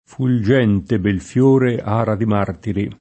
martire
martire [ m # rtire ] s. m. e f.